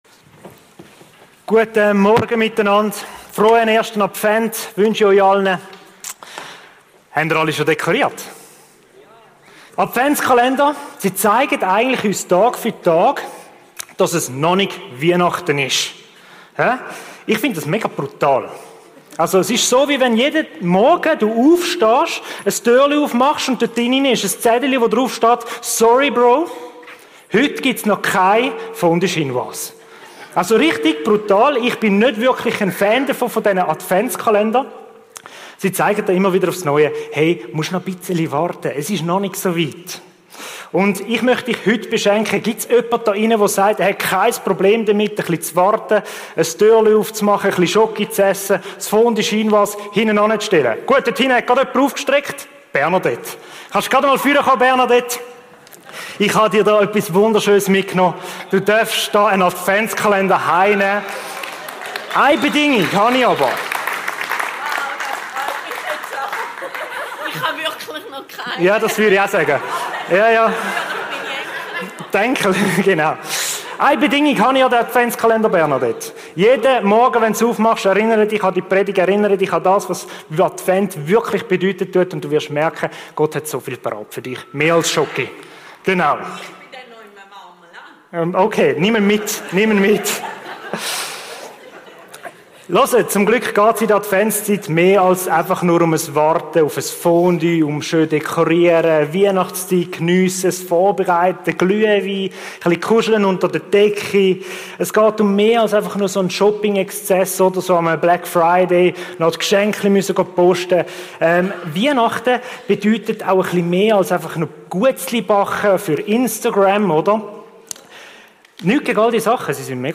Regelmässig die neusten Predigten der GvC Frauenfeld